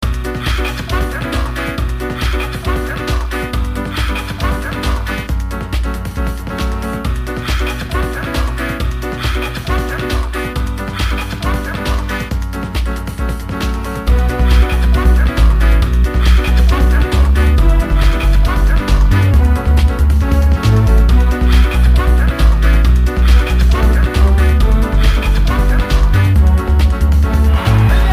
old-skool bassy piano-rave track